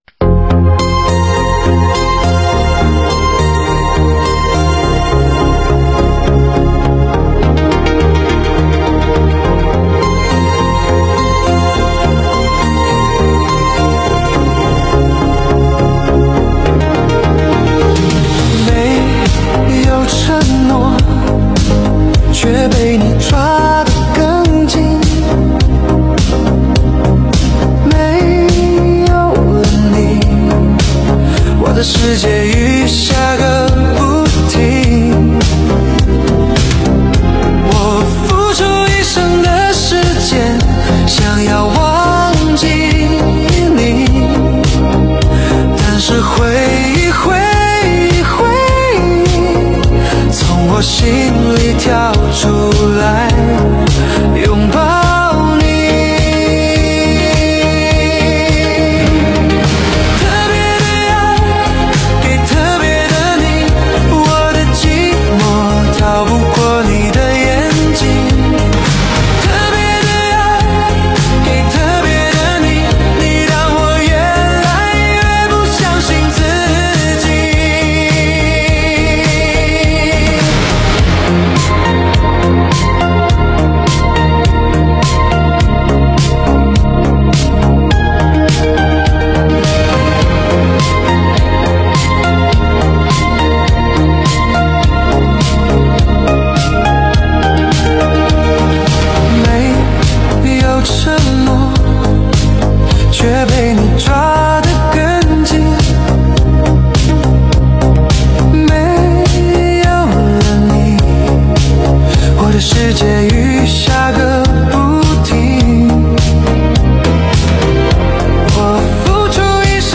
十首都是高音质